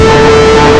1 channel
00018_Sound_destructivenoise.mp3